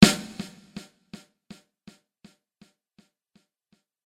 軽快な音色。
スネアドラム02 着信音